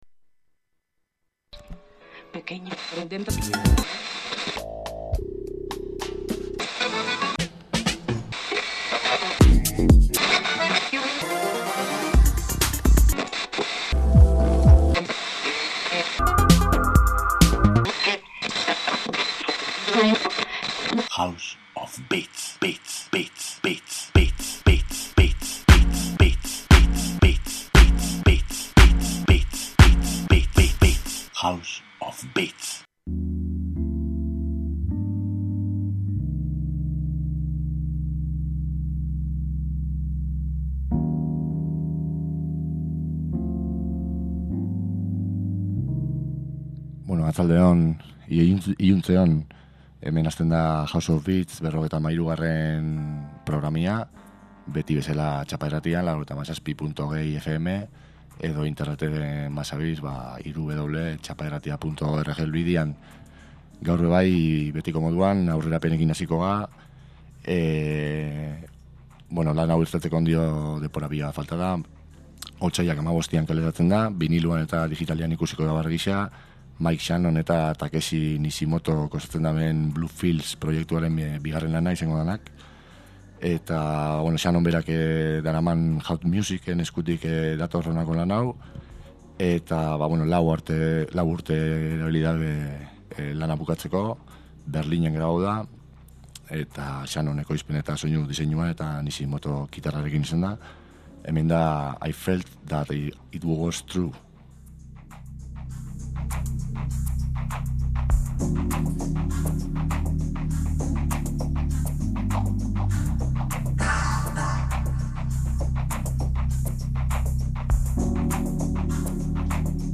Txapa irratian elektronika kultura sustatzen duen irratsaioa. Elkarrizketak, sesioak, jai alternatiboen berriak eta musikaz gozatzeko asteroko saioa.